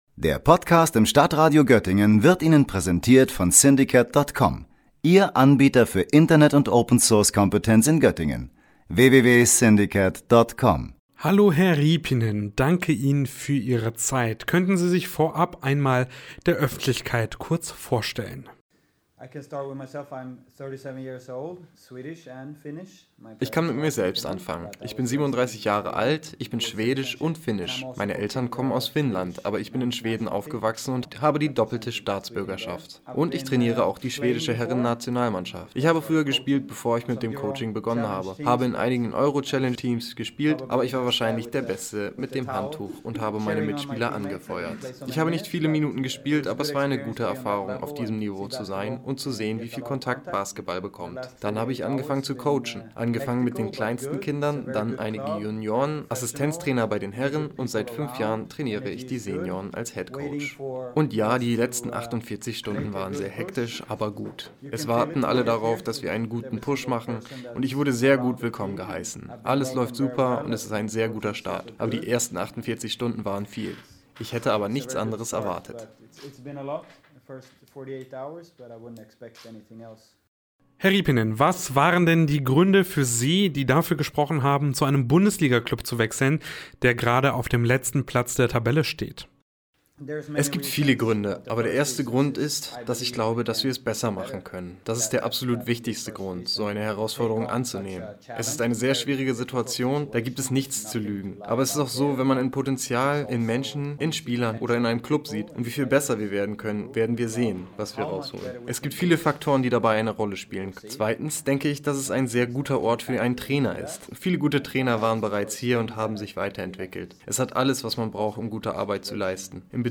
Im Interview